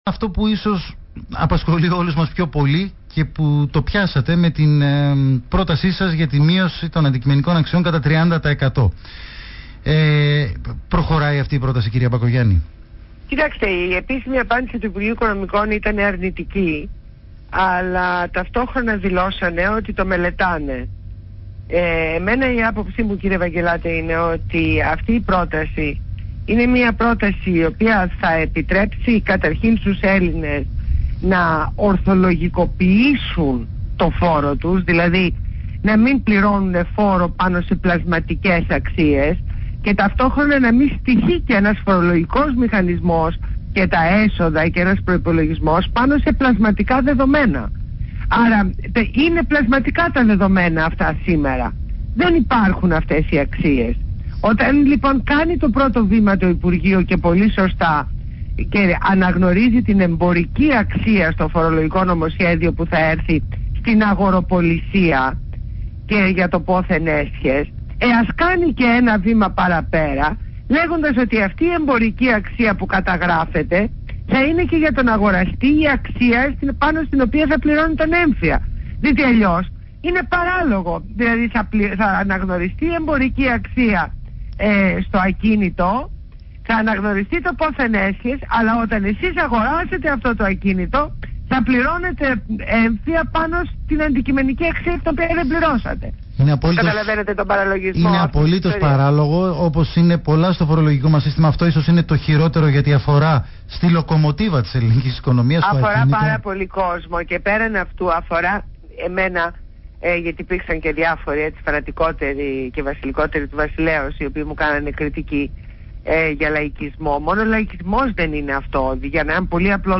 Συνέντευξη στο ραδιόφωνο Παραπολιτικά 90,1fm στον Ν. Ευαγγελάτο